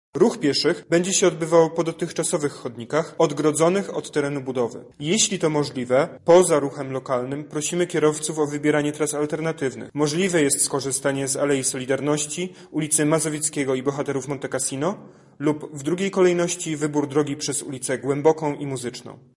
-mówi